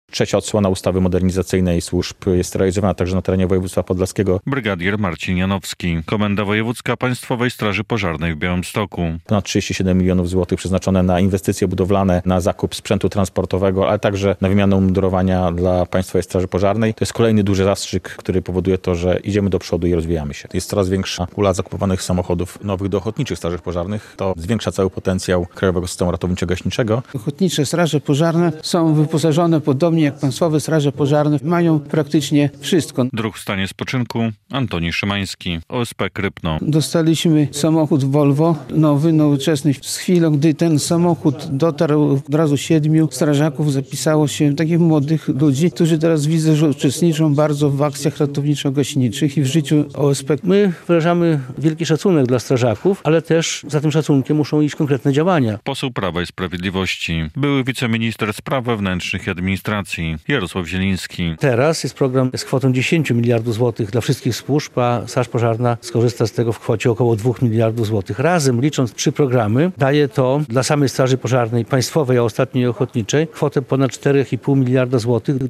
Rola Państwowej Straży Pożarnej - konferencja w Suwałkach
relacja